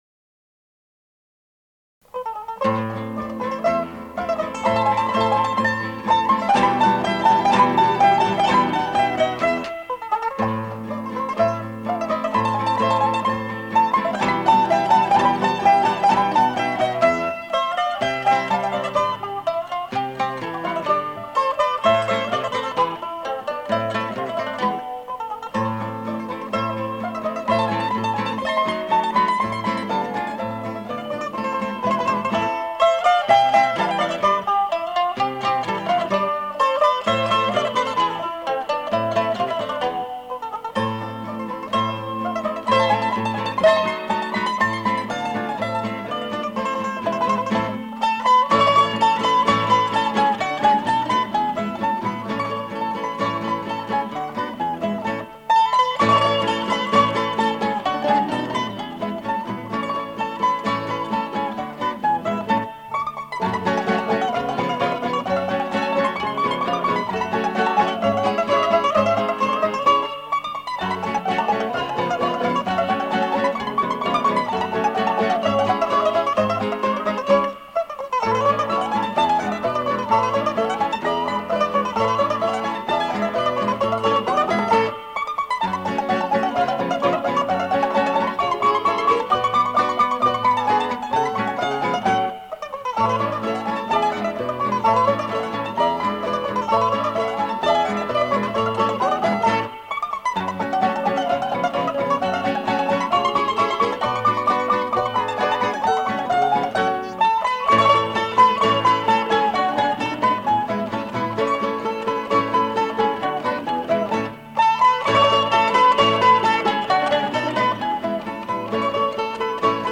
primo mandolino.
mandola
chitarra bolognese.